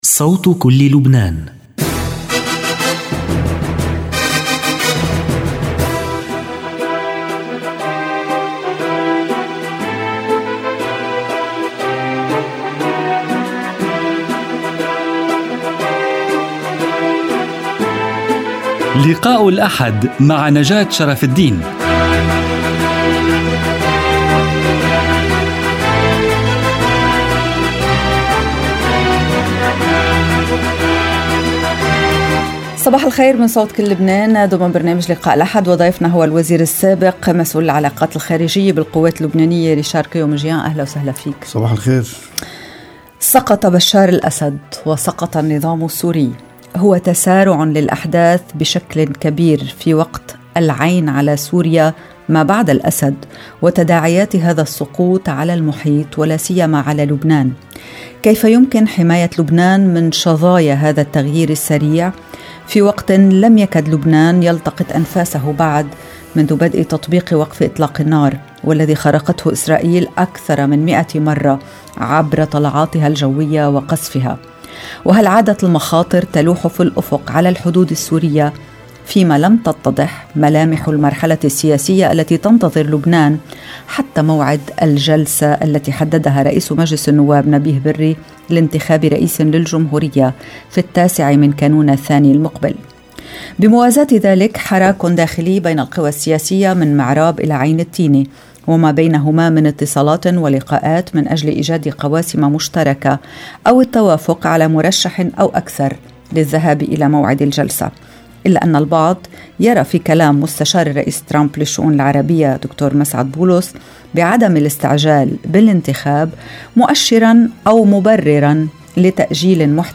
لقاء الأحد رئيس جهاز العلاقات الخارجية في القوات اللبنانية الوزير السابق ريشار قيومجيان Dec 08 2024 | 00:57:01 1x 00:00 / 00:57:01 Subscribe Share RSS Feed Share Link Embed